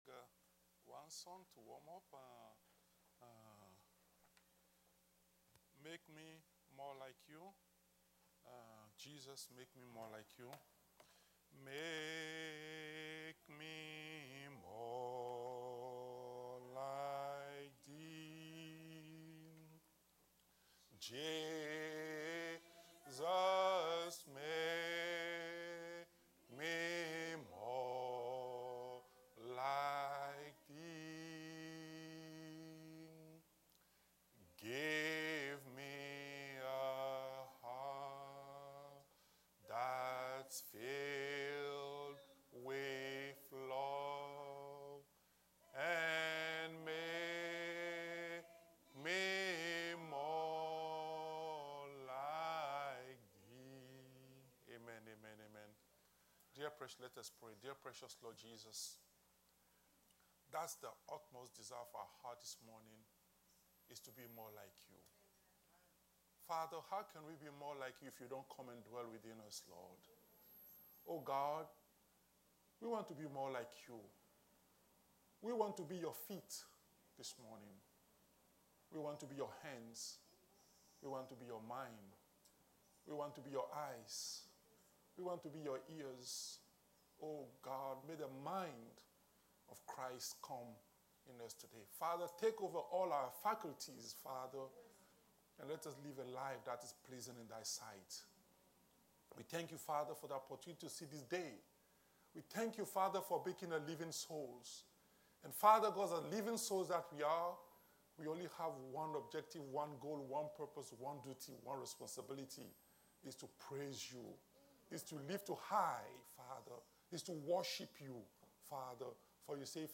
Series: Sunday school